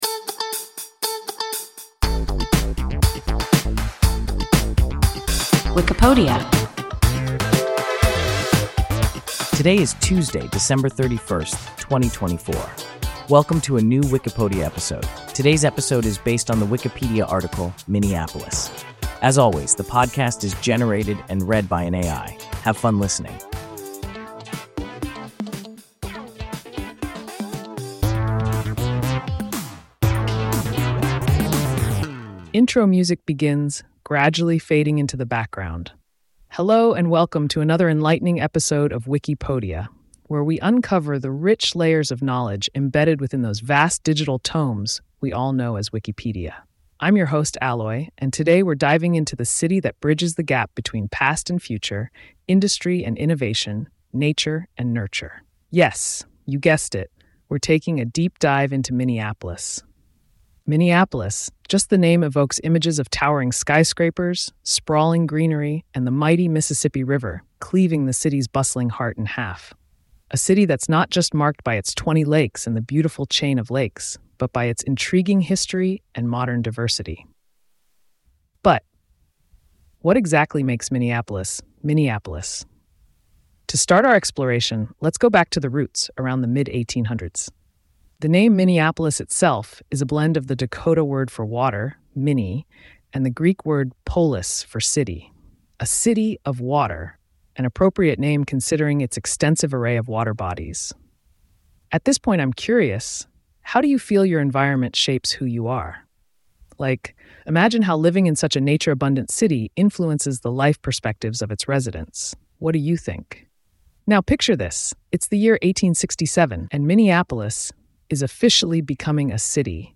Minneapolis – WIKIPODIA – ein KI Podcast